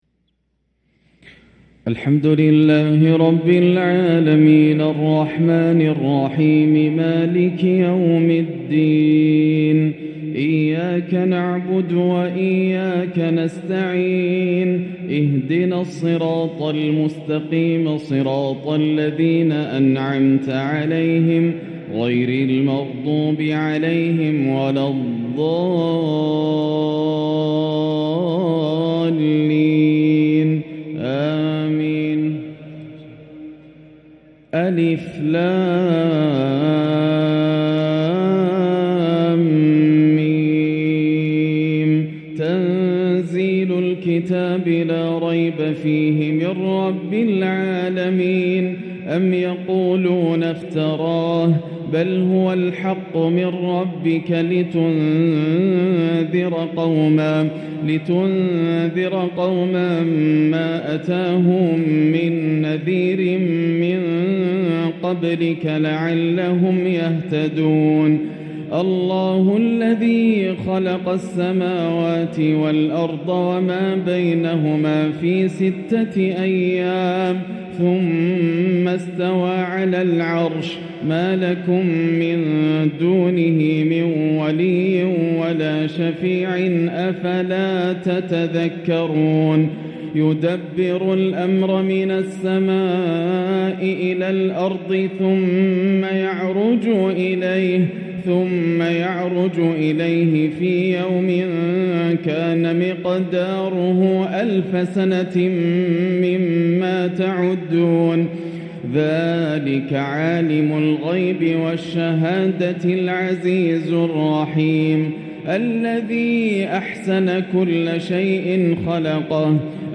تلاوة لسورتي السجدة والإنسان | فجر الجمعة 9-2-1445هـ > عام 1445 > الفروض - تلاوات ياسر الدوسري